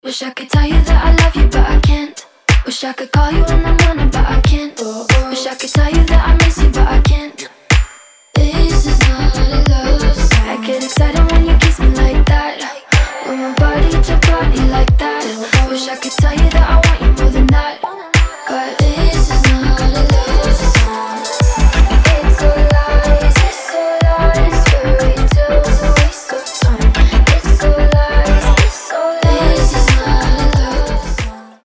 • Качество: 320, Stereo
поп
ритмичные
мелодичные
Midtempo
alternative pop
красивый женский голос
Electropop